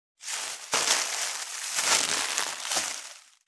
658ゴミ袋,スーパーの袋,袋,買い出しの音,ゴミ出しの音,袋を運ぶ音,
効果音